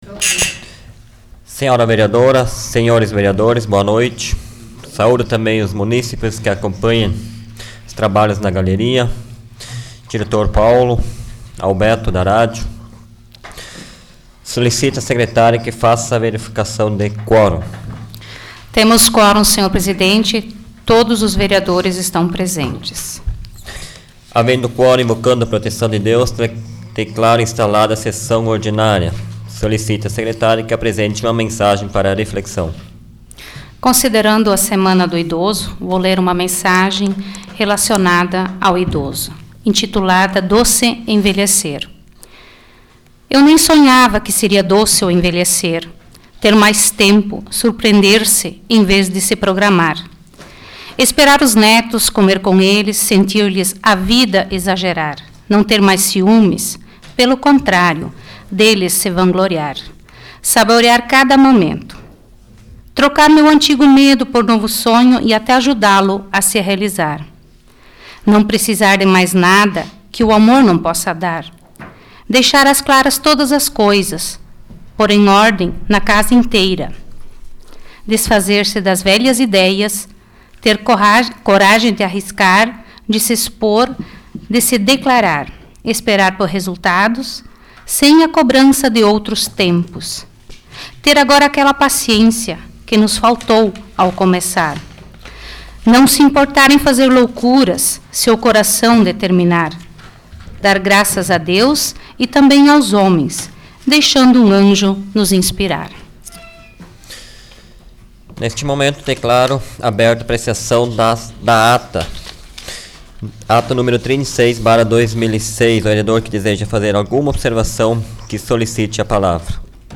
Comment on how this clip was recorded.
Áudio da 62ª Sessão Plenária Ordinária da 12ª Legislatura, de 25 de setembro de 2006